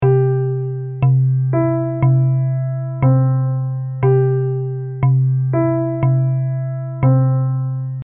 Punktierung
Im folgenden Beispiel sehen Sie eine punktierte Viertelnote, gefolgt von einer Achtelnote. Zusammen füllen beide Noten zwei Schläge, werden aber ungleichmäßig verteilt: Der erste Ton klingt länger, der zweite deutlich kürzer:
Audiobeispiel_Punktierung.mp3